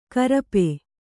♪ karape